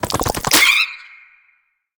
Sfx_creature_penguin_skweak_13.ogg